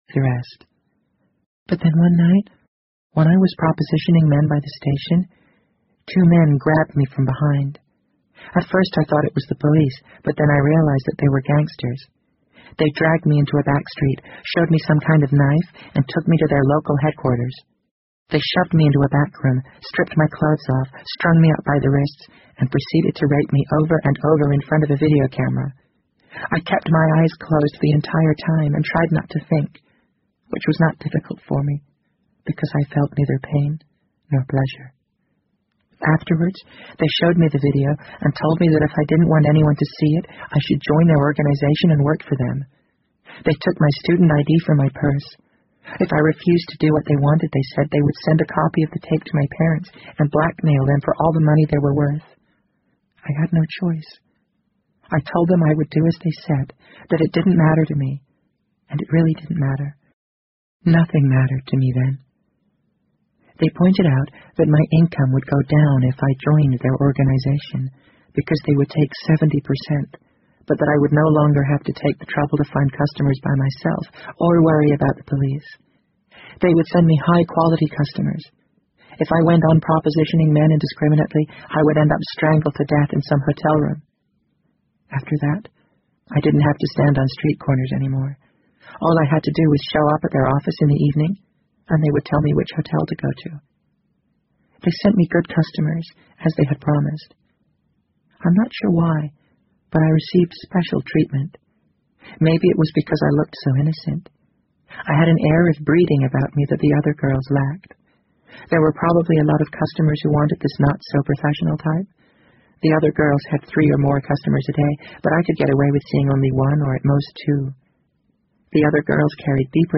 BBC英文广播剧在线听 The Wind Up Bird 49 听力文件下载—在线英语听力室